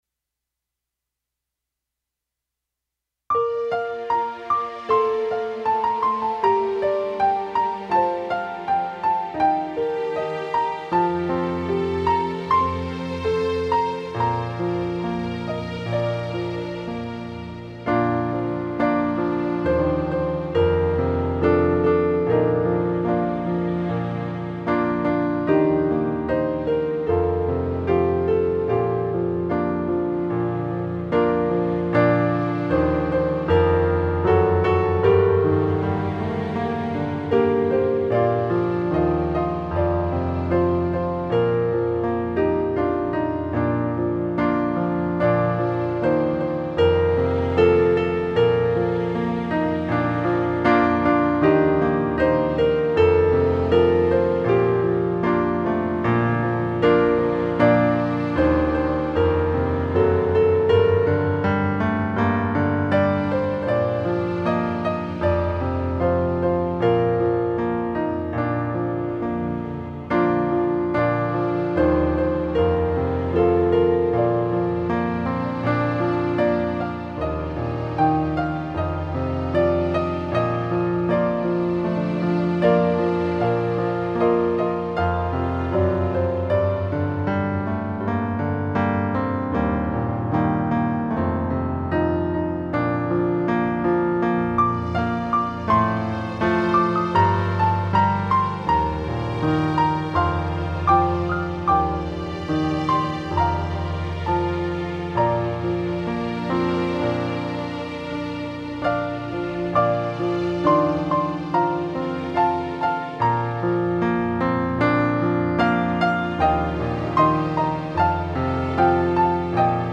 Playback - Hino 258
▶ Teclado: Yamaha PSR-650
▶ Estúdio: Durley Music